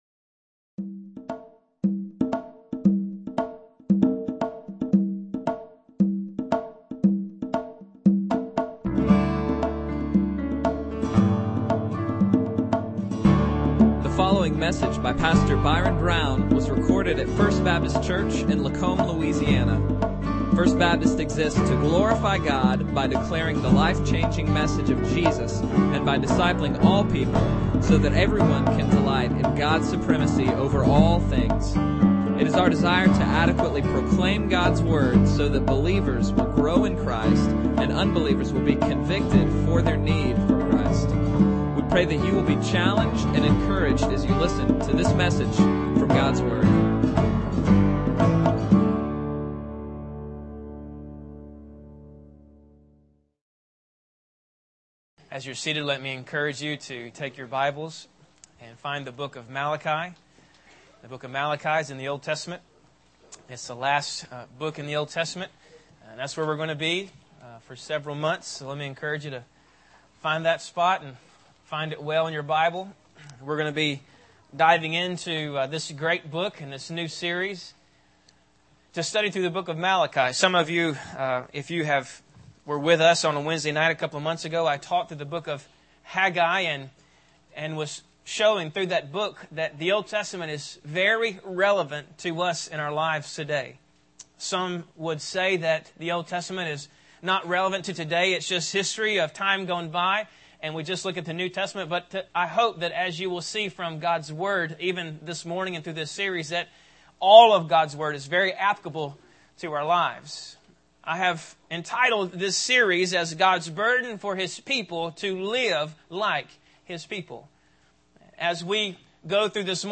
Bible Text: Malachi 1:1 | Preacher: